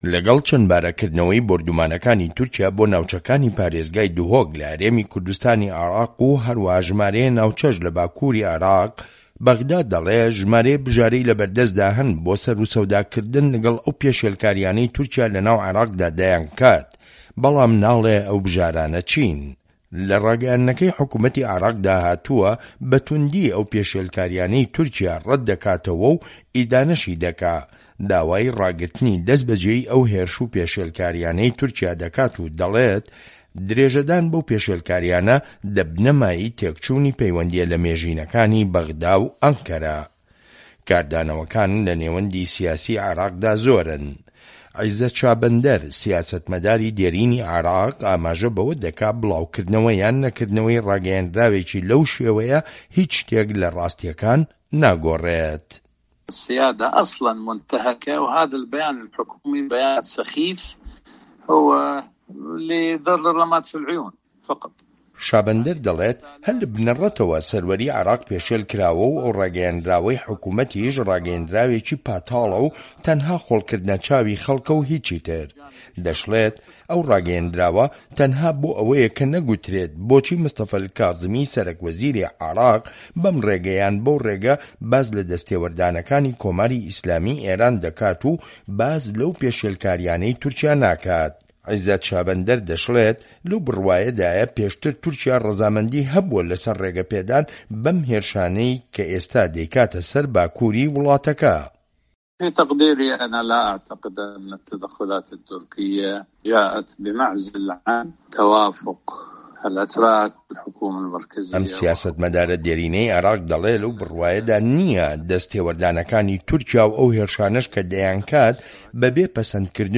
ڕاپۆرتی ڕاگەیەندراوەکەی عێراق لەسەر تورکیا ناڕەزایی لێدەکەوێتەوە